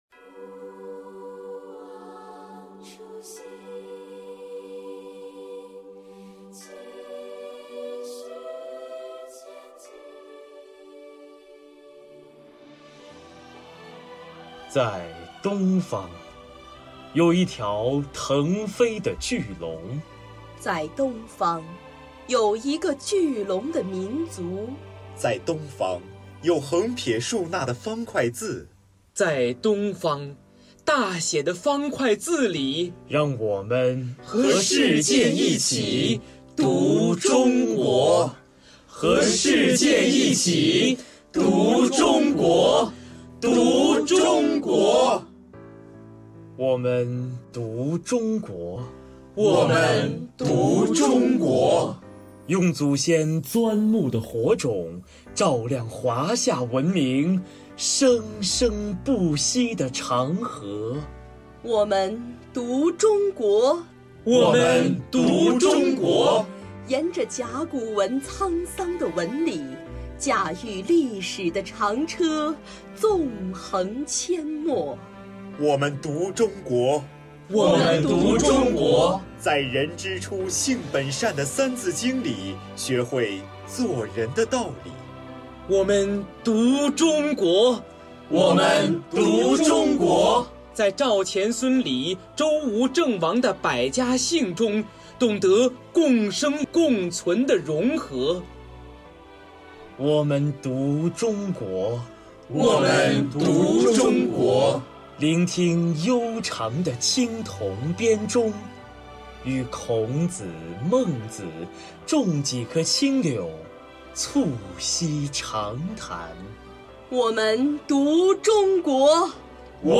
《讀中國》朗誦示範 - GAPSK
《讀中國》錄音示範
《讀中國》錄音示範-1.mp3